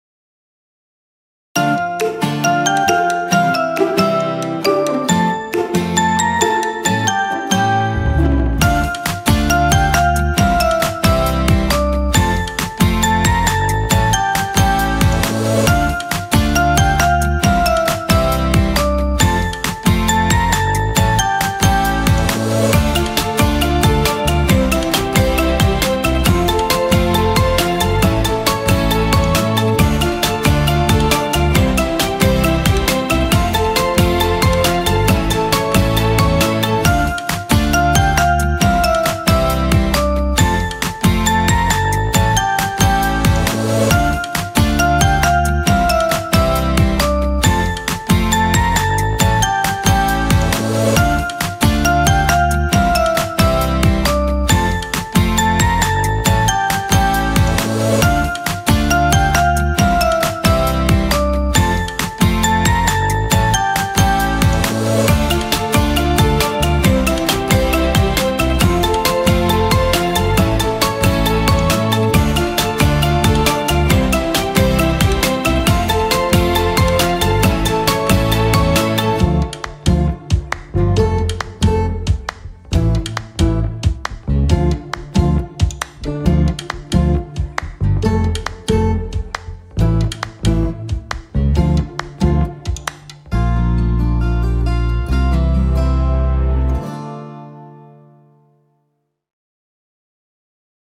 tema dizi müziği, mutlu neşeli eğlenceli fon müziği.